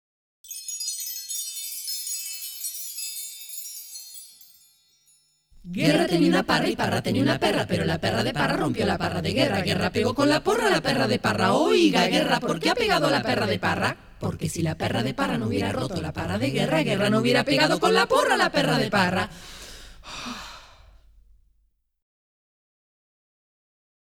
Música tradicional
Música vocal